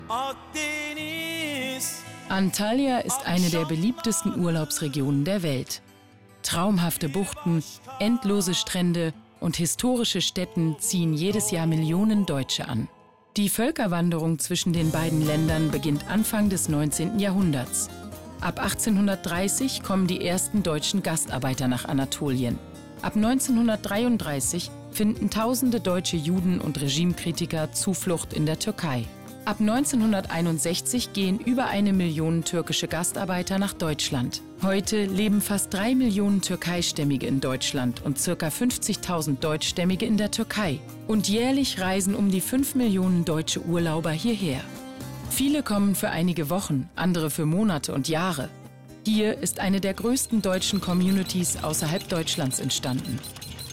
dunkel, sonor, souverän, markant, sehr variabel
Doku